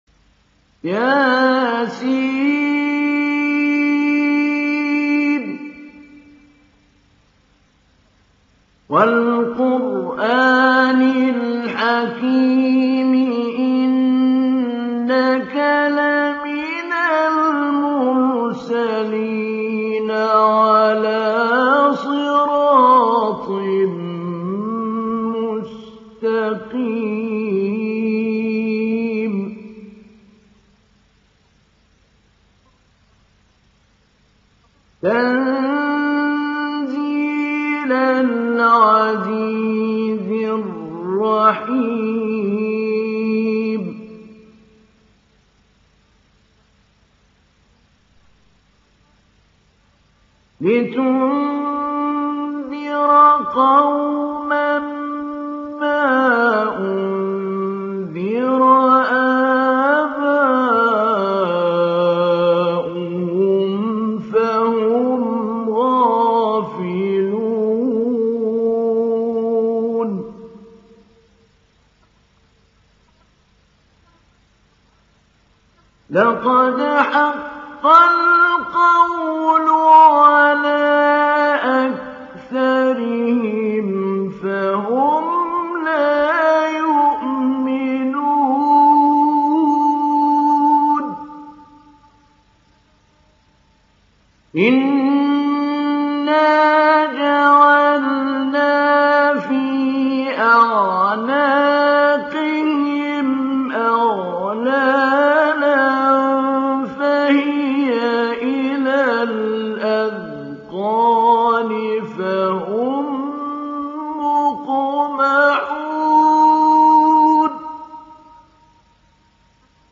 تحميل سورة يس محمود علي البنا مجود